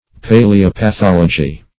Definition of paleopathology. What does paleopathology mean? Meaning of paleopathology. paleopathology synonyms, pronunciation, spelling and more from Free Dictionary.